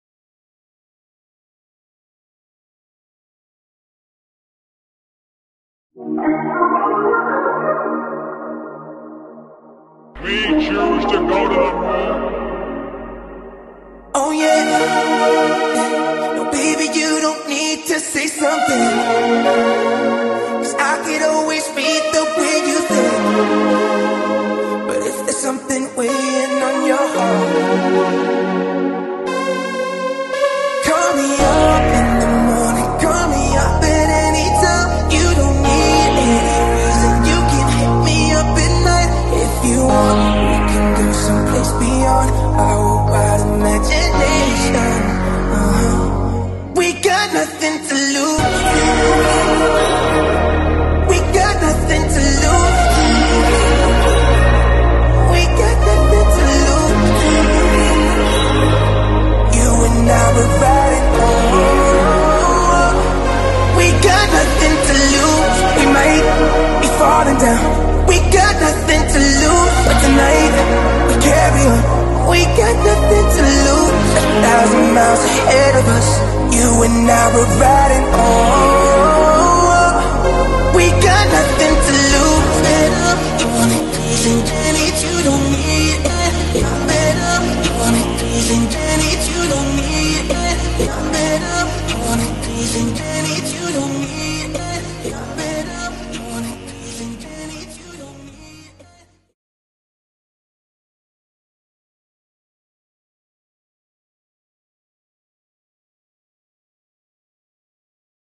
Es geht um die Chords und den Lead Sound. Lead bei 0:14 und Chord bei: 0:31. Wäre meeega dankbar wenn mir da jemand n Tipp geben könnte oder mit diesen 80s klängen vertraut ist.